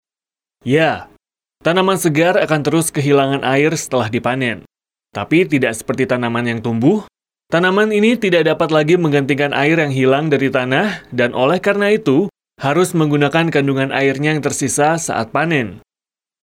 Indonesian Voice Over Talent
Sprechprobe: eLearning (Muttersprache):